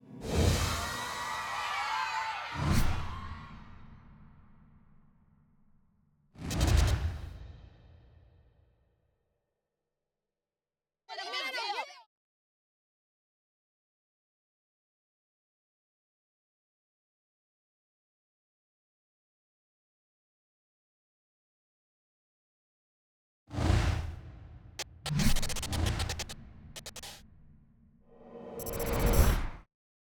HWD_MARATHON_30_v6_ST SFX.wav